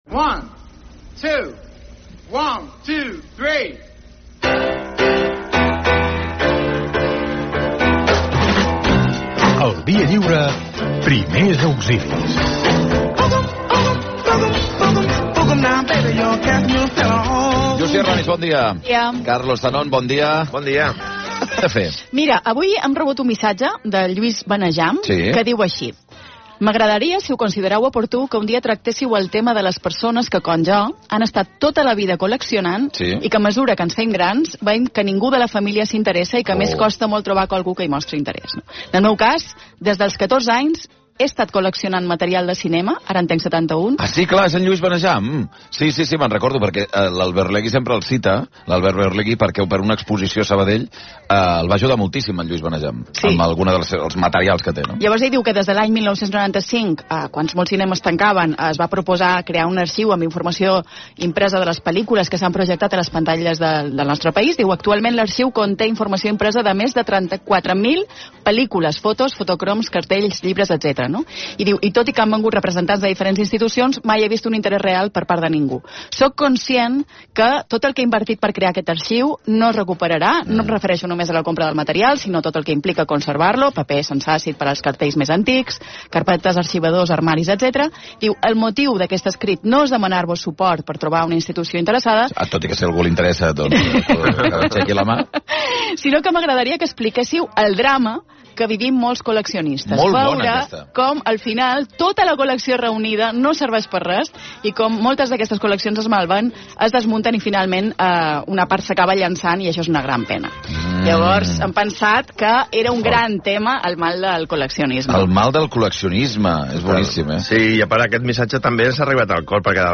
El día 8 de febrero de 2026 leyeron y comentaron la carta en antena, dentro de una sección que titularon “mal del coleccionismo”.